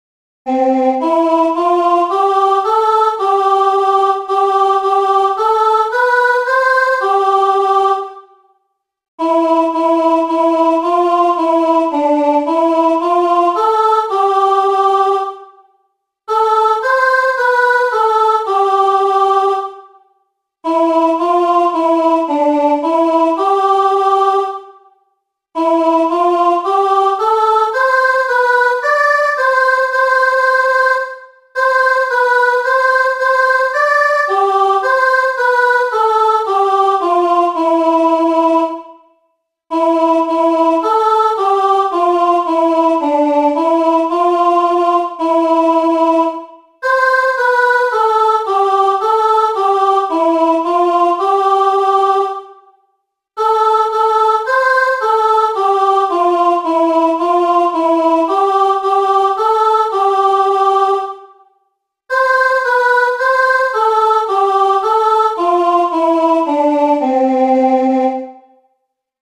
Mary’s parish do this Marian chant for Advent, so for my education I looked up the Gregorian version.
My backing is just the vocal line: